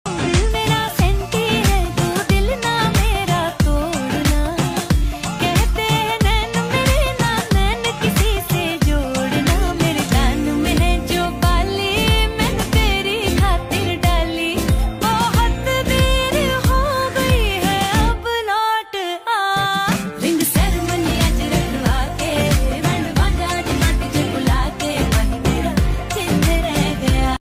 Popular Punjabi viral ringtone with clear sound.